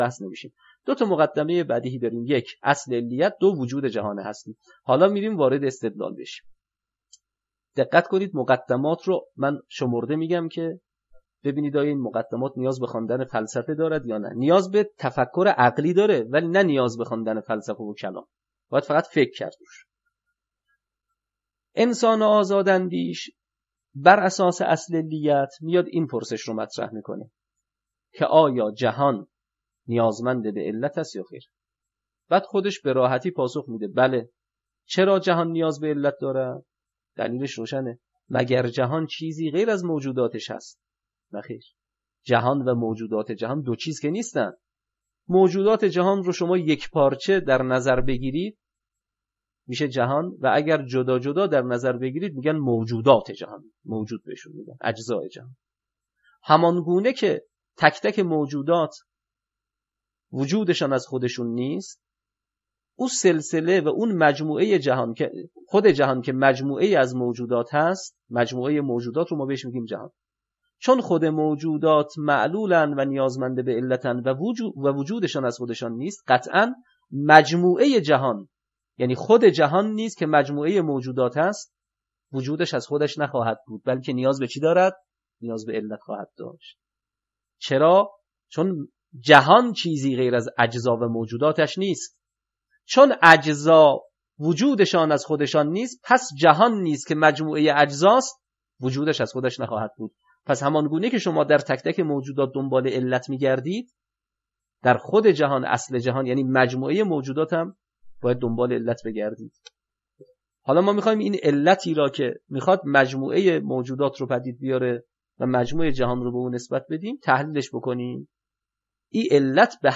تدریس عقاید استدلالی یک